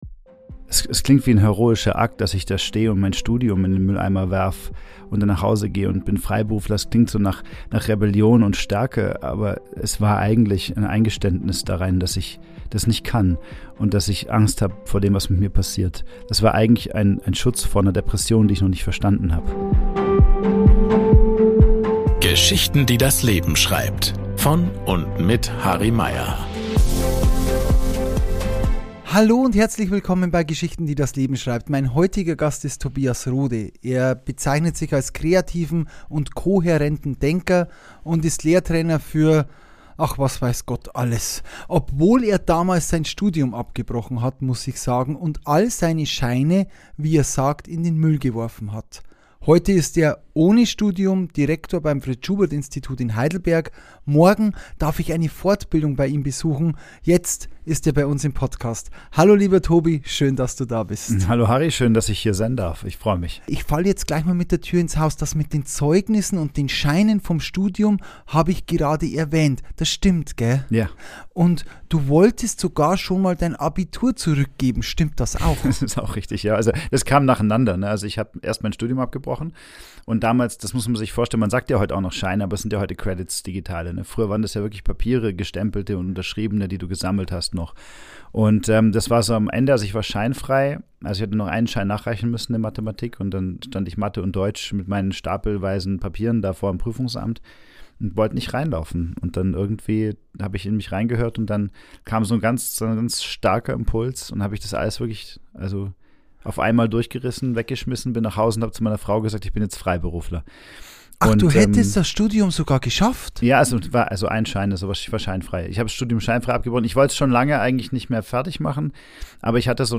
Ein Gespräch über Prägung, Verletzlichkeit – und den leisen Beginn einer späteren Stärke.